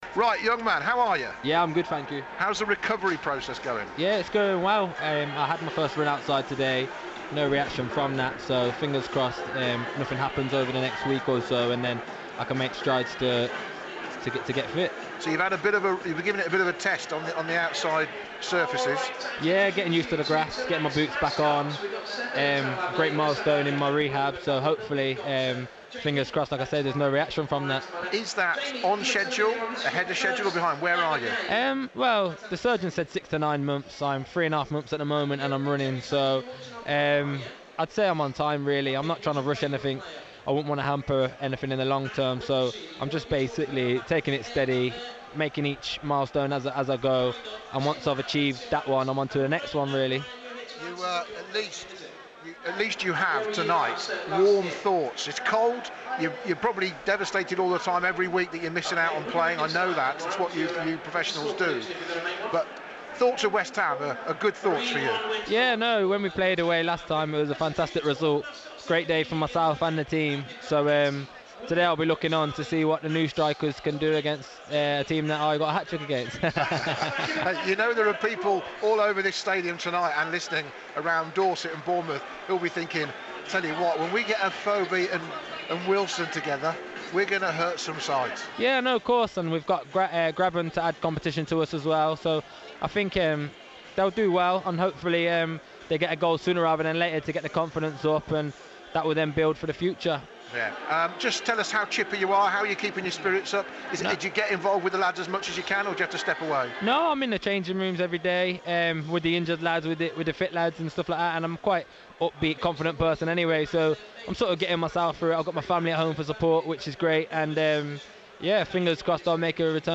Bournemouth striker Callum Wilson, who was ruled out for at least six months after injuring his knee against Stoke tells Radio Solent he is making progress with his recovery